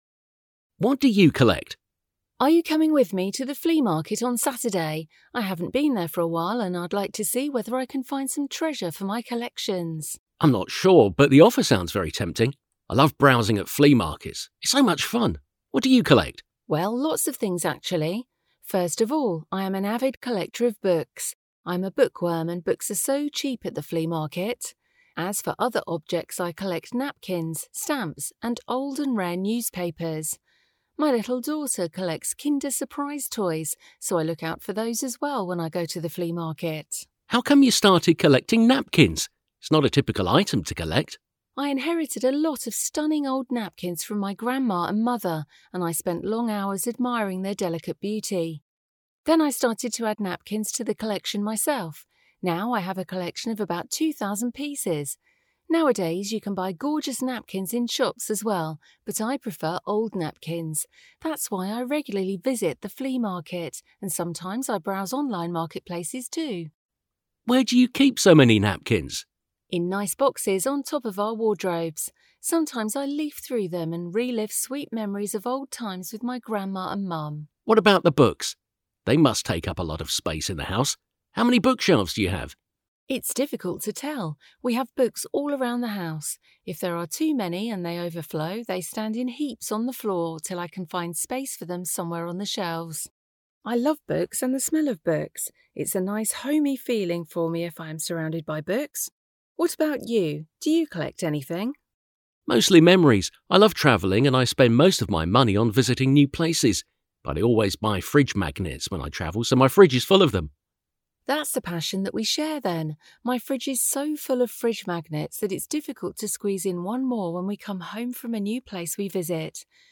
A magazin 82. oldalán található párbeszédet hallgathatod meg itt.